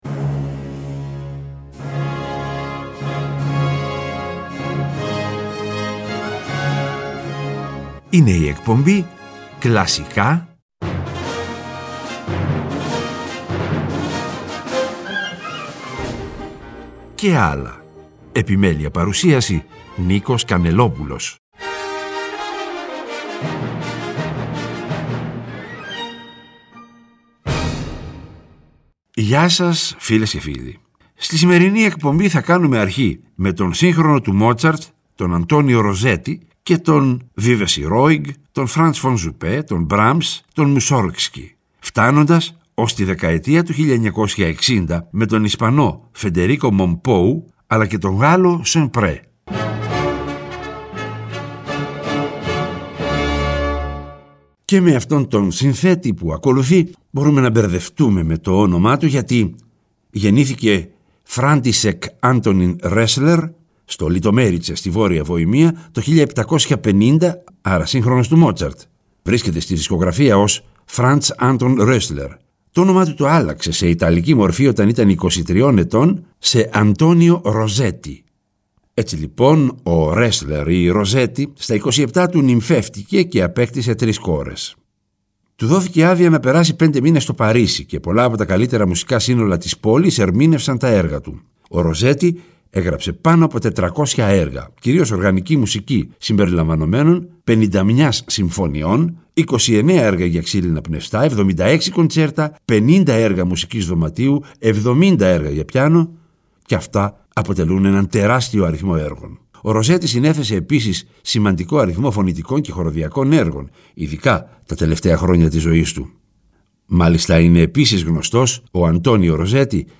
Κλασικη Μουσικη